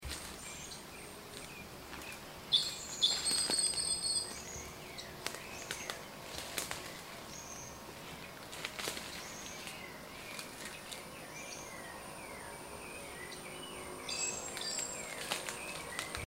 Blacksmith Thrush (Turdus subalaris)
Life Stage: Adult
Location or protected area: Pan de Azúcar
Condition: Wild
Certainty: Recorded vocal